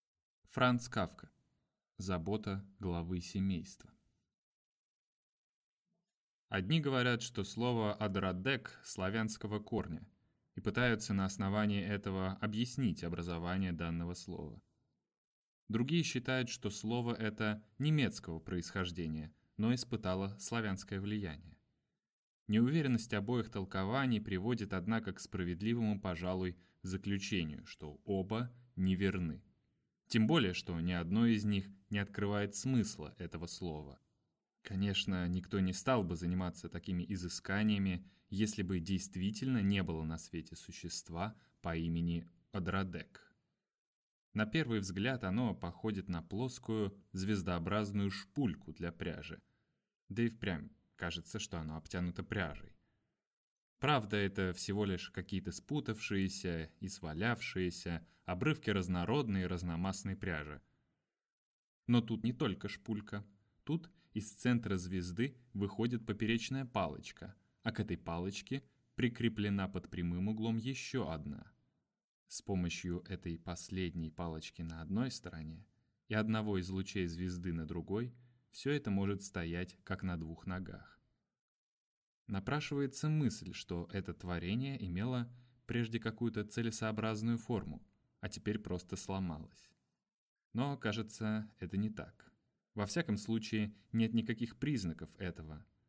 Аудиокнига Забота главы семейства | Библиотека аудиокниг
Прослушать и бесплатно скачать фрагмент аудиокниги